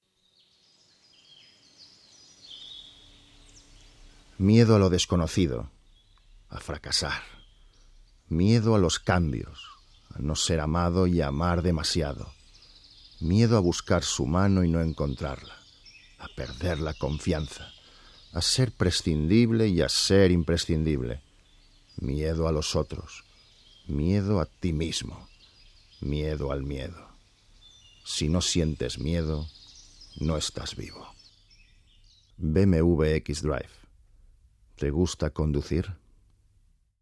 Voz masculina grave con mucha personalidad.
Sprechprobe: Industrie (Muttersprache):
Warm and masculine voice, very versatile both for dramatic and comedy roles.